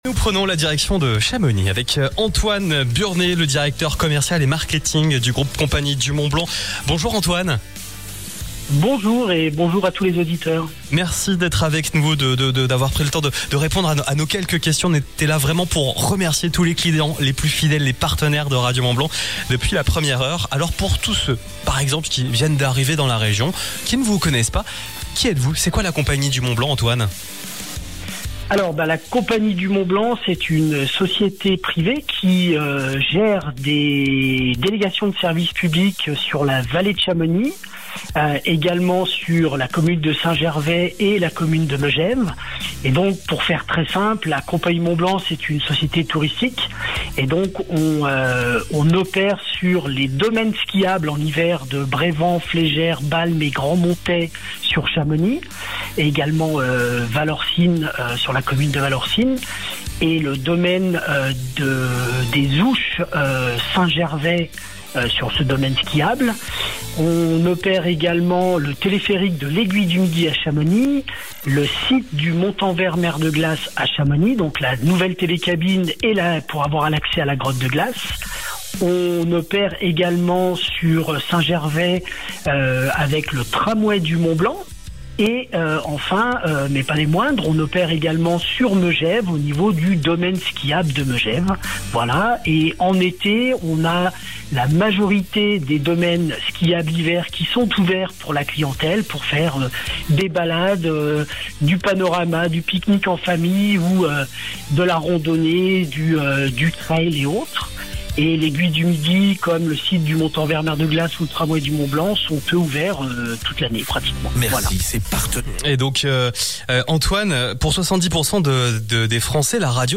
À l’occasion de notre soirée de remerciements clients, Radio Mont Blanc a déroulé le tapis rouge à ceux qui font vivre le territoire, innovent, entreprennent et nous font confiance tout au long de l’année.
Interview